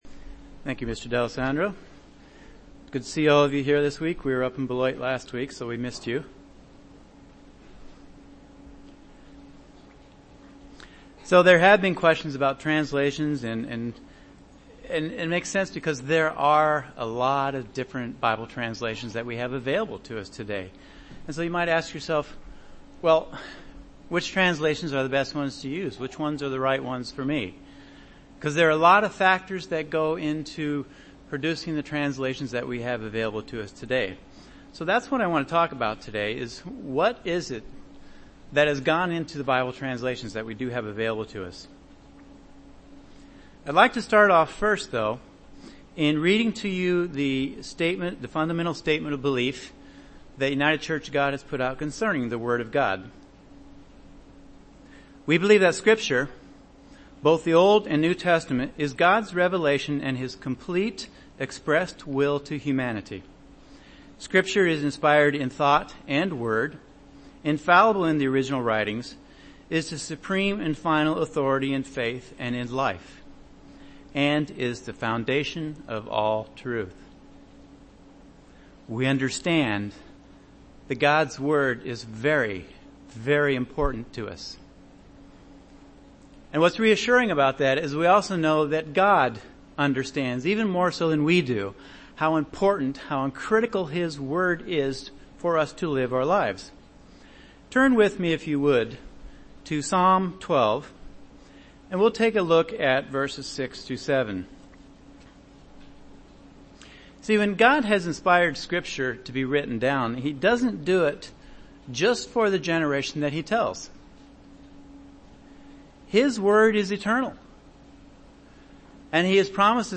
This sermon discusses some of the factors to consider when choosing which Bible translation we use for our studies of God’s Word.